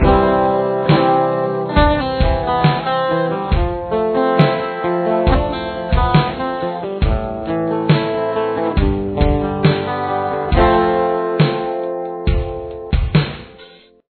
Rhythm